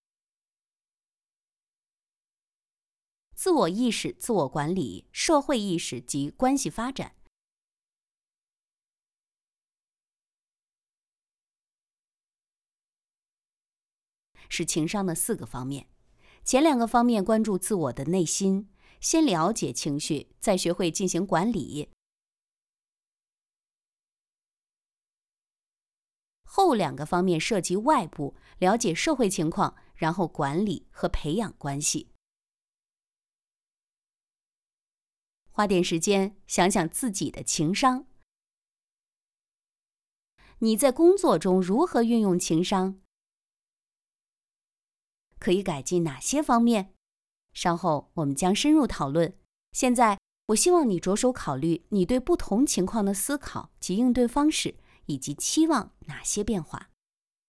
Chinese_Female_041VoiceArtist_2Hours_High_Quality_Voice_Dataset
Text-to-Speech